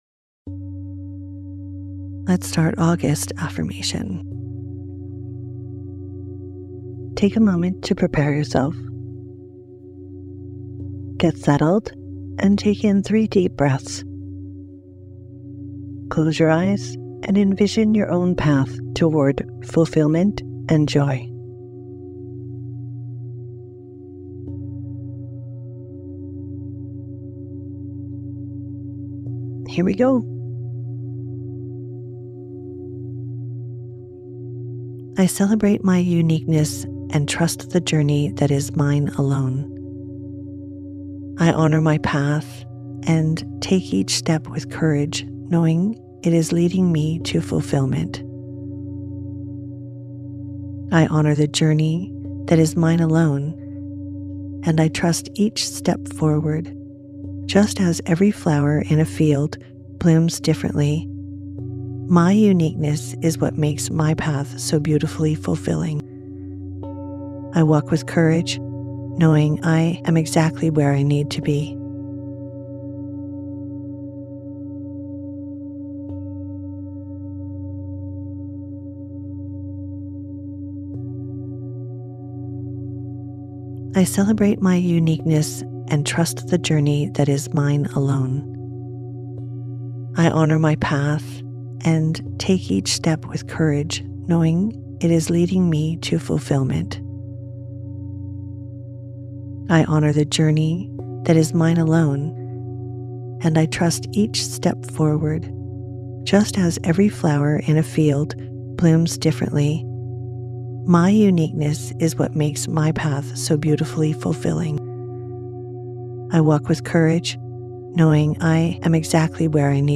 Sleep versions feature the affirmation repeated three times, creating repetition for deeper impact and greater benefits.